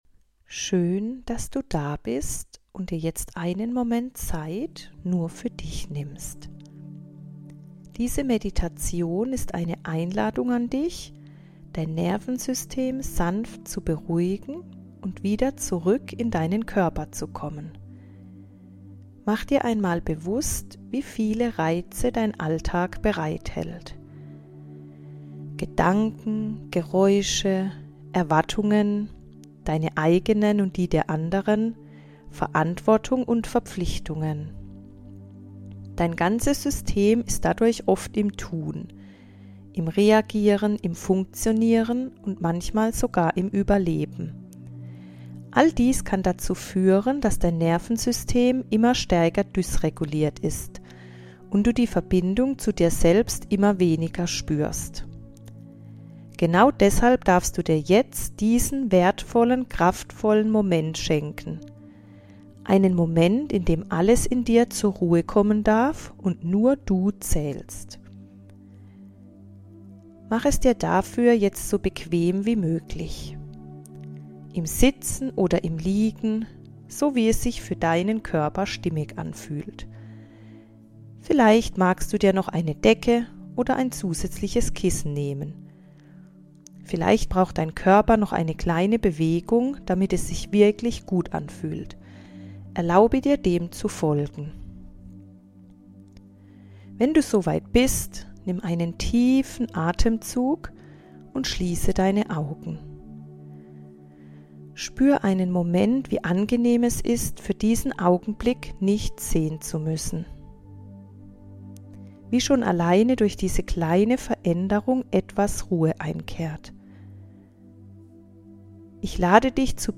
Meditation Beruhige dein Nervensystem ~ Gefühlsreise Podcast